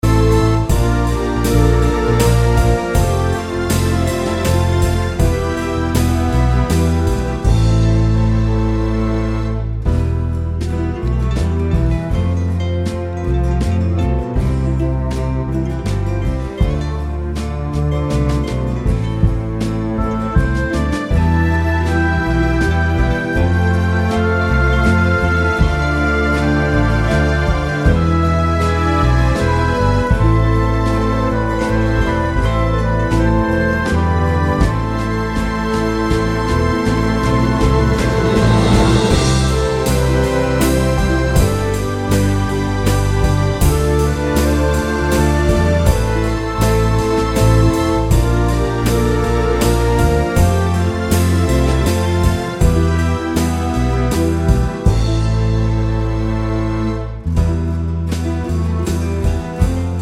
no Backing Vocals Crooners 2:32 Buy £1.50